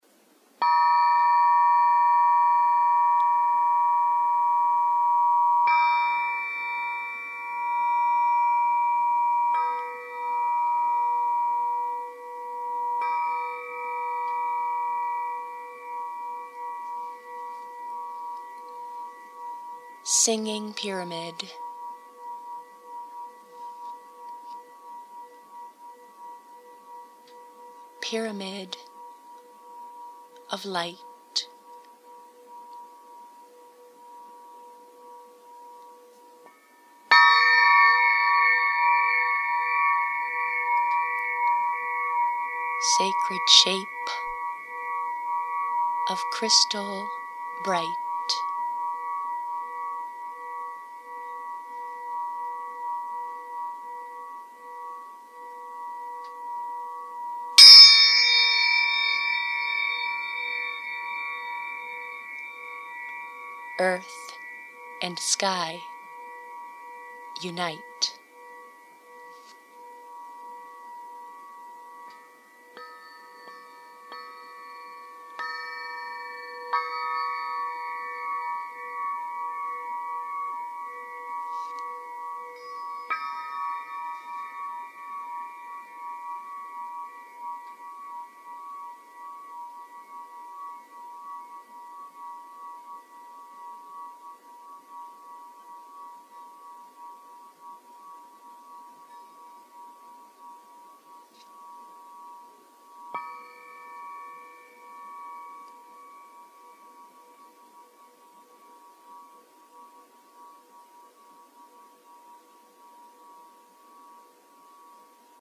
eadf7-singing_pyramid-haiku.mp3